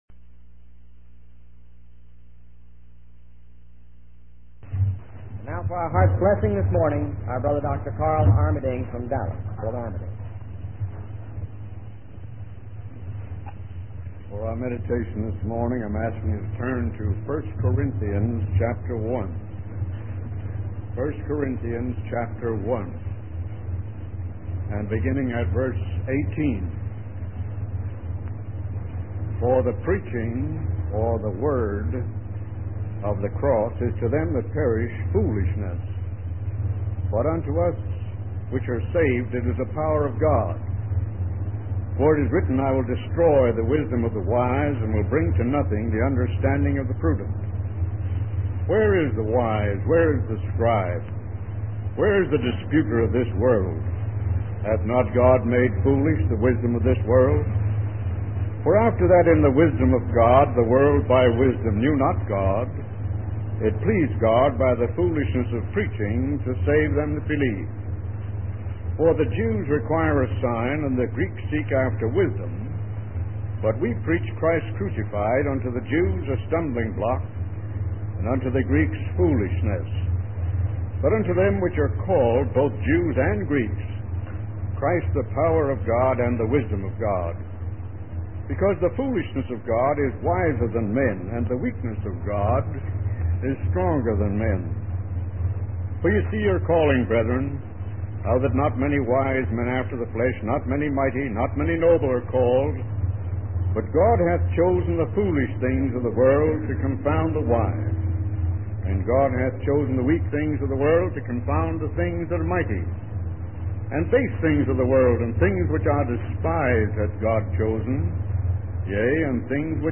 In this sermon, the speaker discusses the transformative power of the gospel of God's grace. He shares a personal story of a young man who was once deeply involved in sin but was changed by the gospel and became a missionary.